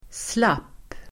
Uttal: [slap:]